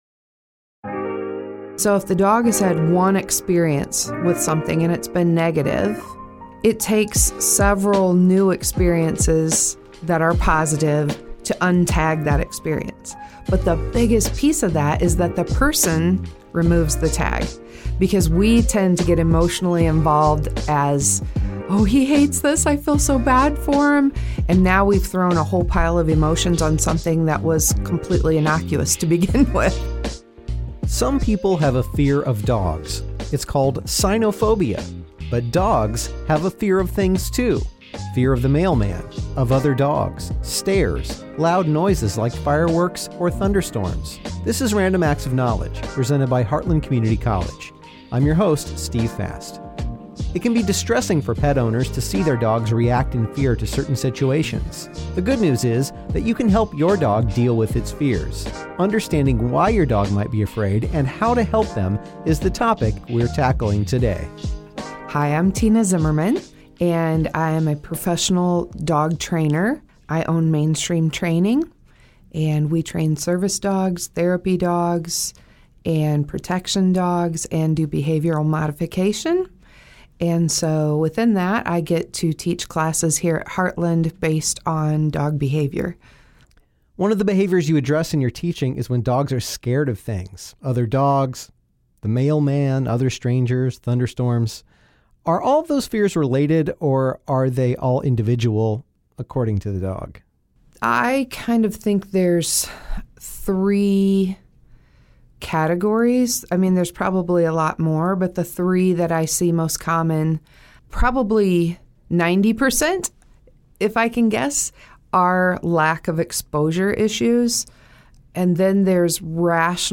Some of what it takes to tackle a dog’s fears requires training for the two-legged members of the household. Professional dog trainer